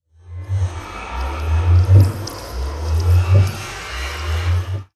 portal.wav